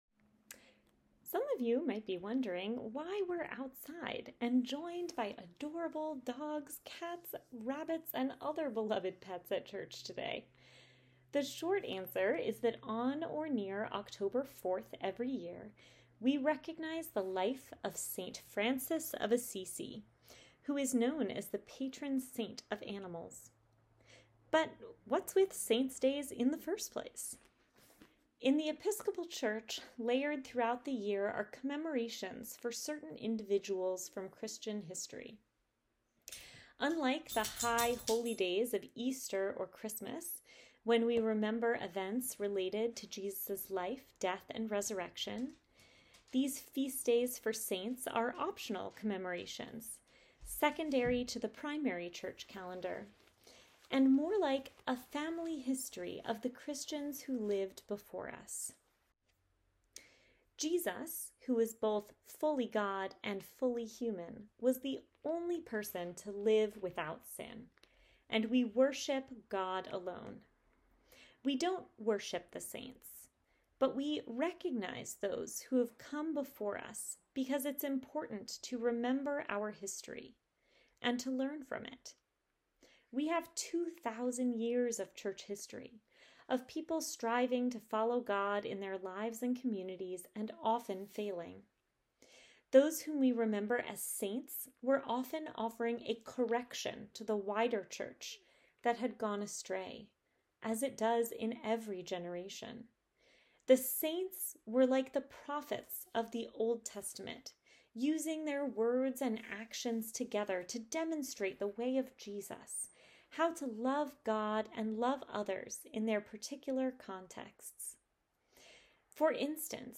Sermon Podcast | Church of St. James the Less
Some of you might be wondering why we are outside and joined by adorable dogs and other beloved pets at church today.